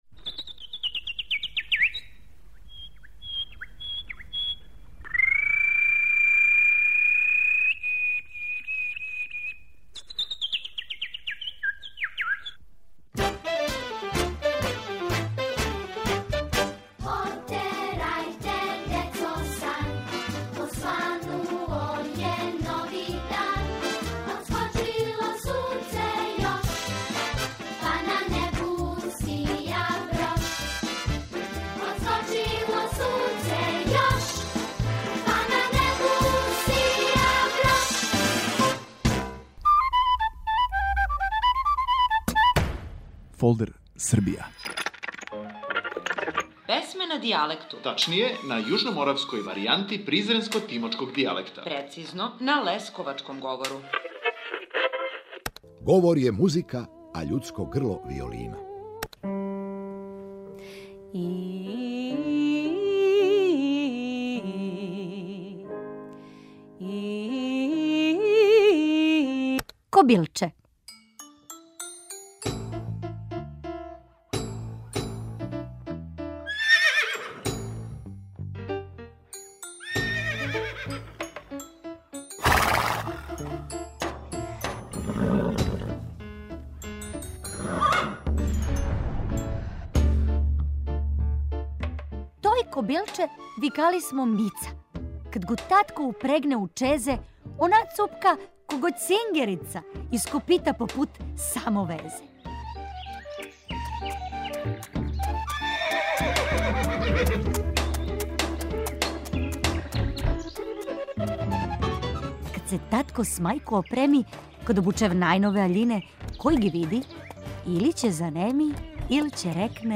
на јужноморавској варијанти призренско - тимочког дијалекта, прецизније - на лесковачком говору.